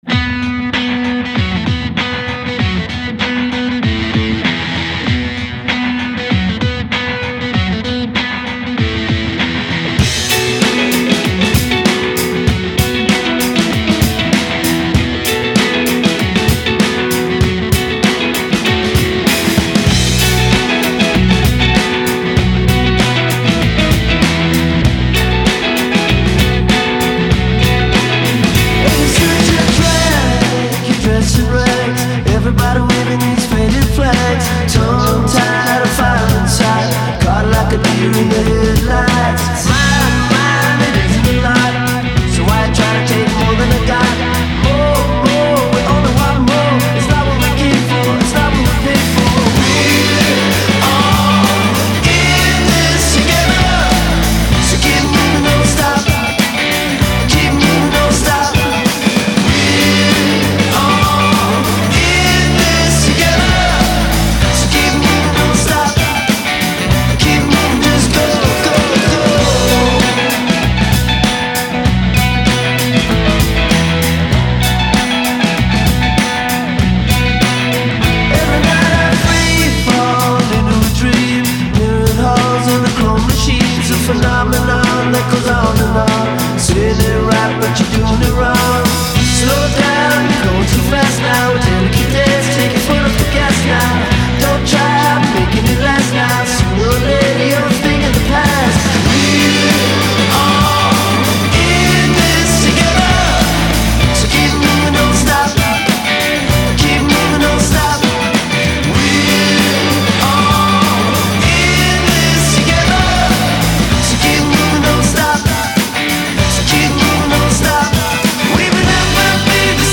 quasi-funky neo-psychedelia